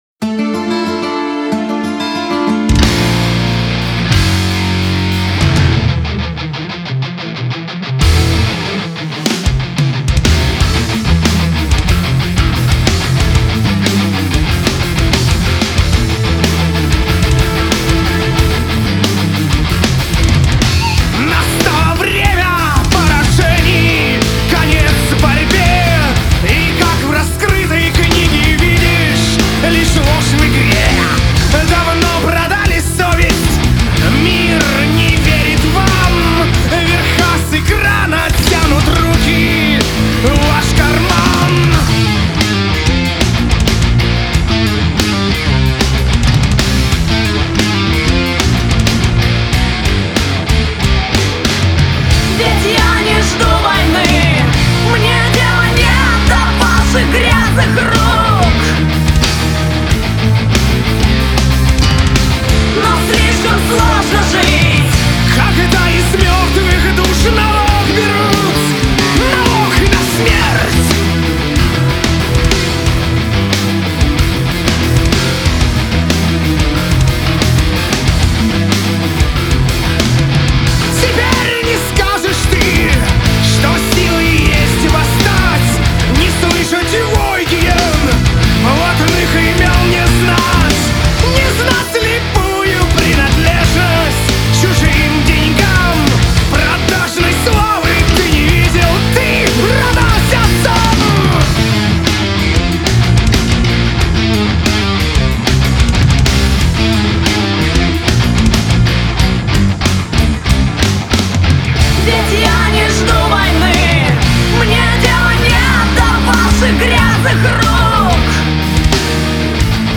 Играет в стилях: Heavy Metal